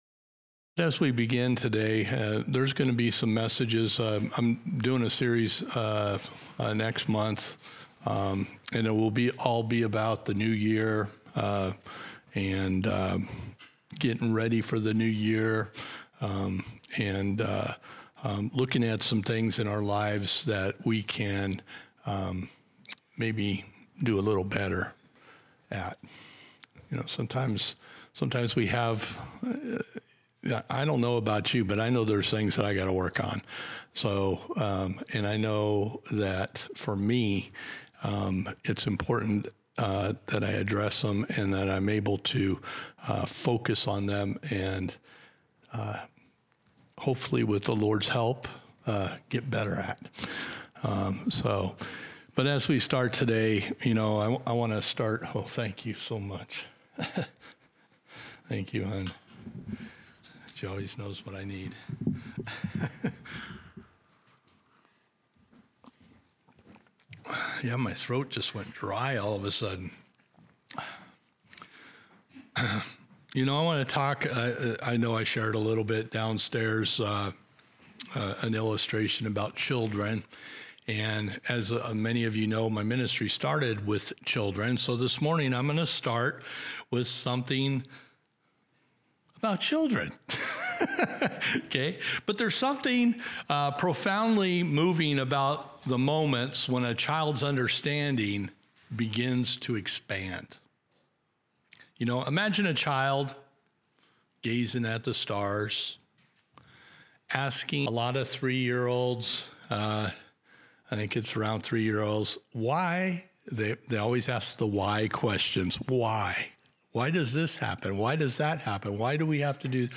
March 31. 2024 Sermon Audio